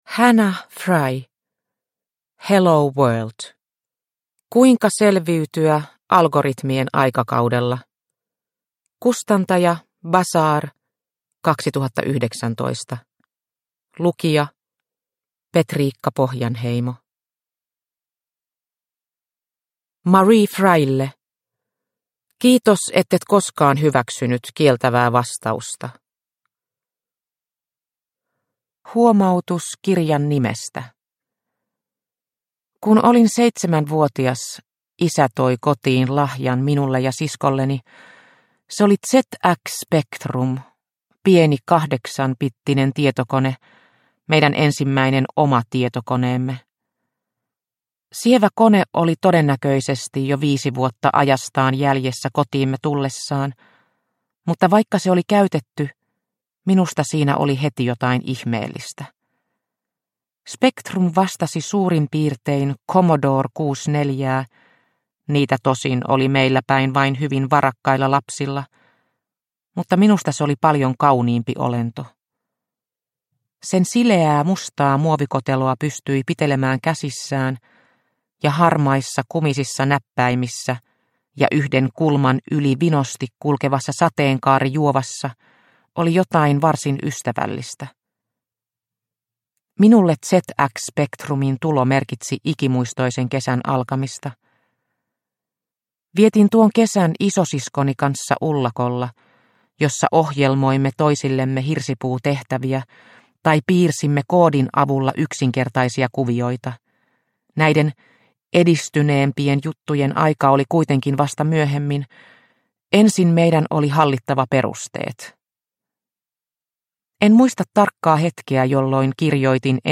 Hello world – Ljudbok – Laddas ner